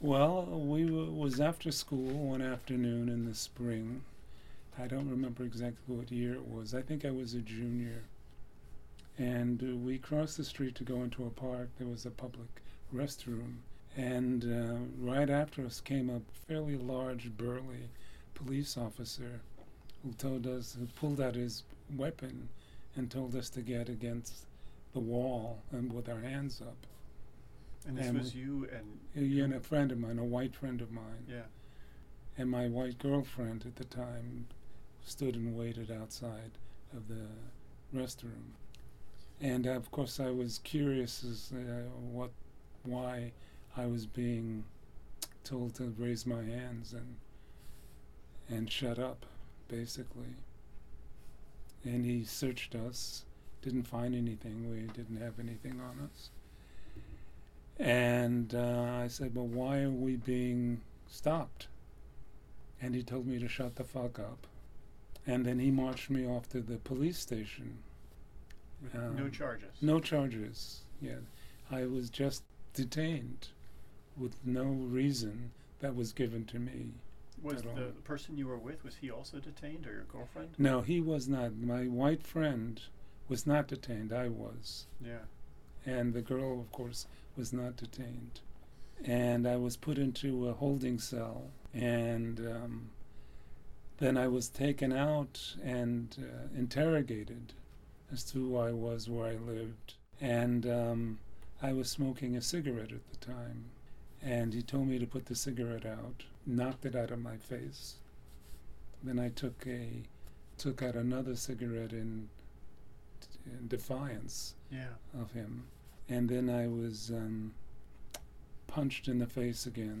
Having said all this,  I am providing two extracted anecdotes from Dr. Sidanius. The first is his description of a horrible incident that occurred while he was a junior in high school.